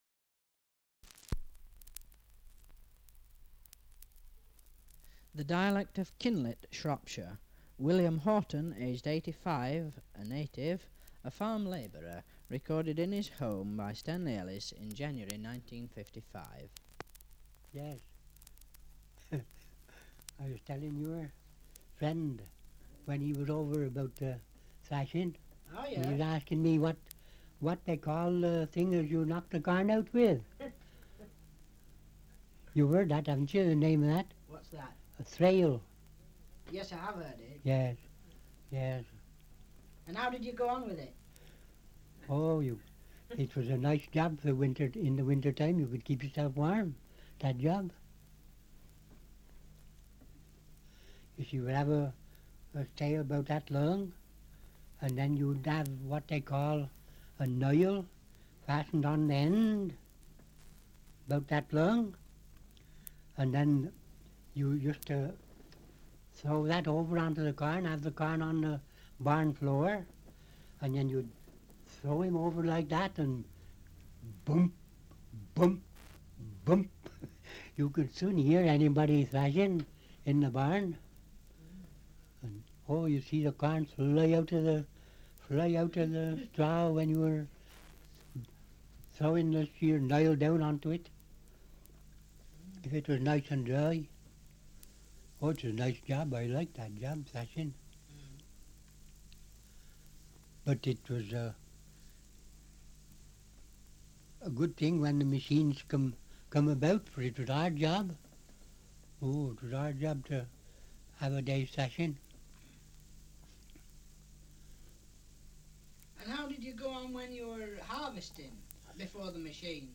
Survey of English Dialects recording in Kinlet, Shropshire
78 r.p.m., cellulose nitrate on aluminium